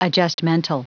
Prononciation du mot adjustmental en anglais (fichier audio)
Prononciation du mot : adjustmental